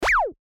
/ F｜演出・アニメ・心理 / F-18 ｜Move コミカルな動き
バスト演出勢いよく現れる 03
プヨン